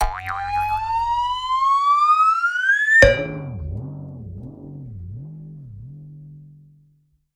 Звуки анимации
Гудок клоуна